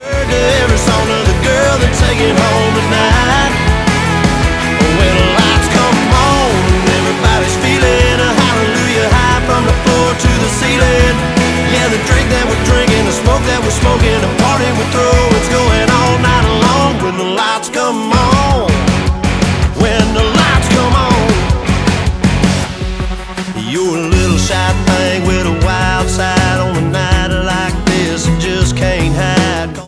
• Country
American country music artist